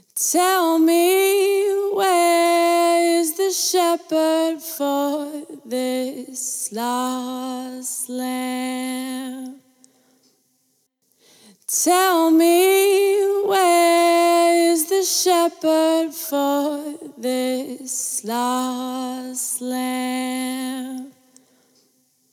Volume-independent Saturation (disabled then enabled)
Saturation is volume-independent. Add same harmonic content for loud and soft signals alike.
couture-saturated-vocals.mp3